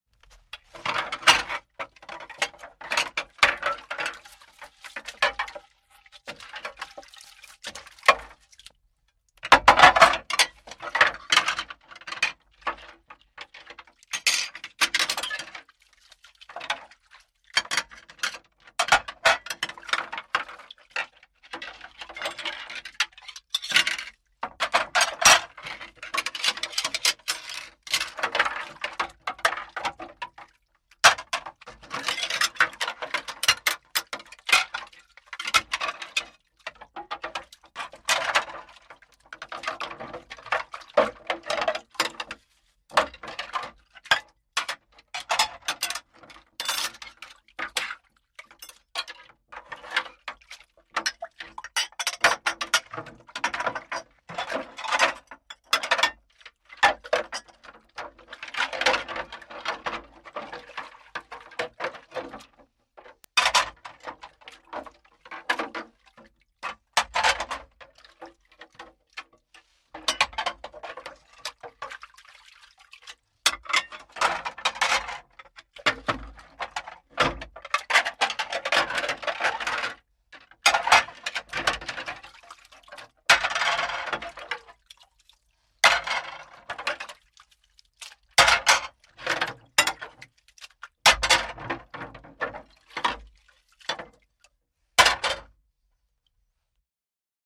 На этой странице собраны натуральные звуки мытья посуды: звон стаканов, стук тарелок, журчание воды.
Мытье посуды в тазике без горячей воды